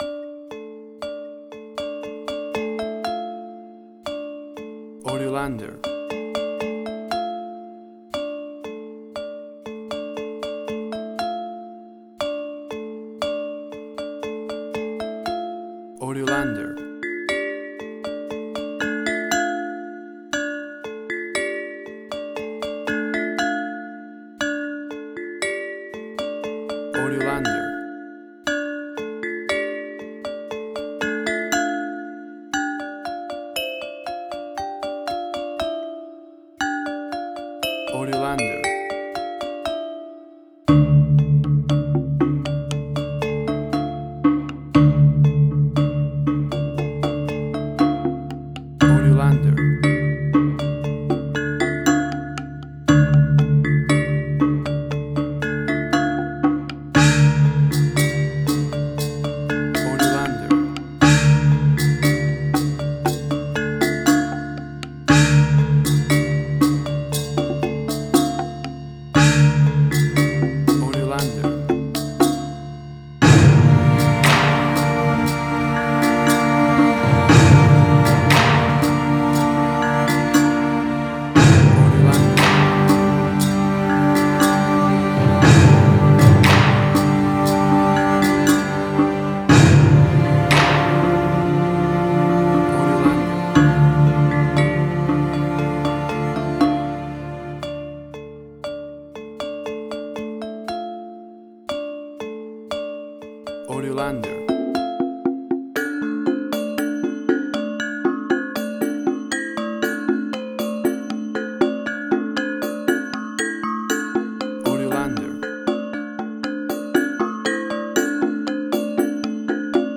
Chinese Action.
WAV Sample Rate: 16-Bit stereo, 44.1 kHz
Tempo (BPM): 118